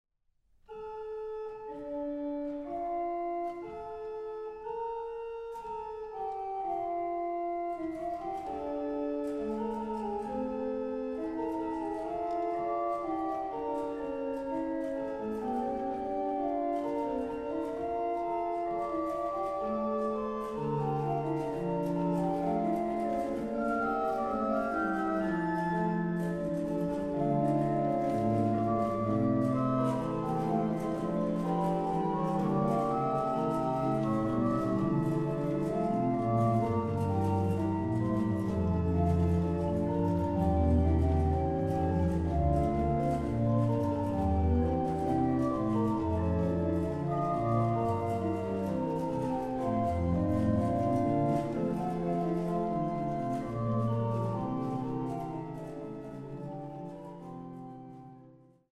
1755 erbaut für Prinzessin Anna Amalia von Preußen